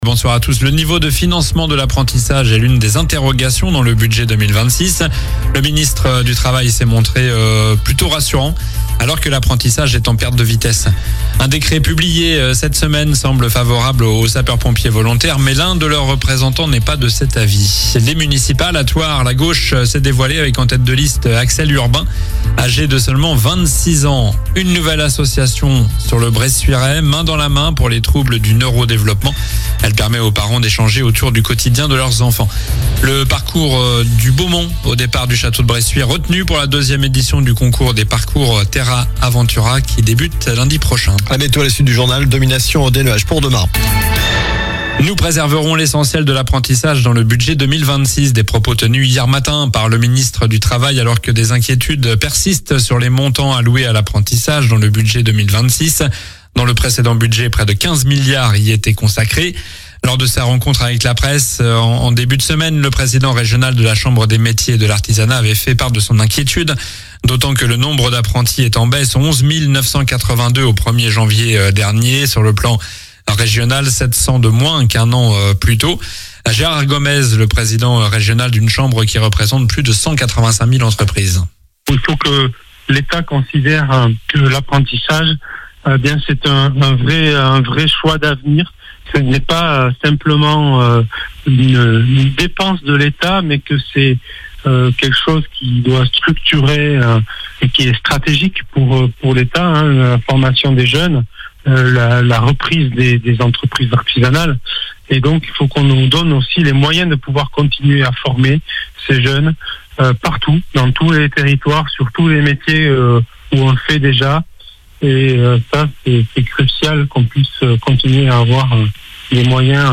Journal du jeudi 22 janvier (soir)